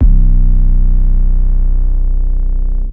OZ-808 (Legend).wav